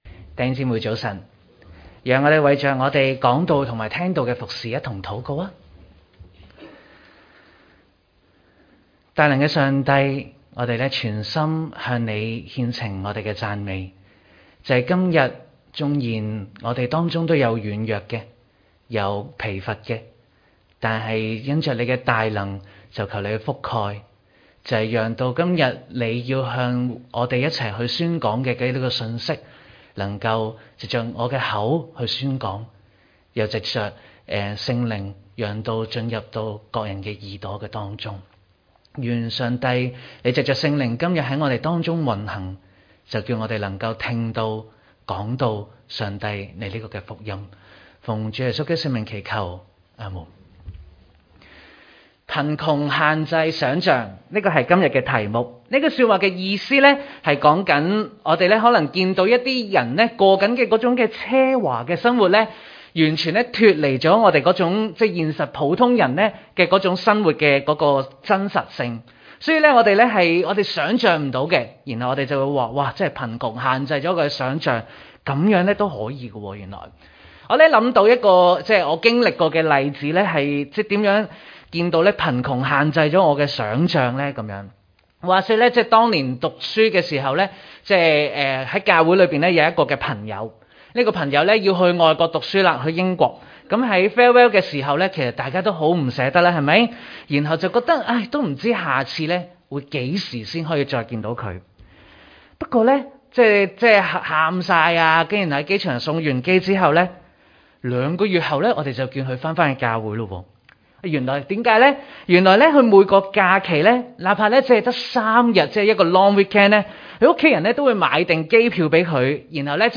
場所：主日崇拜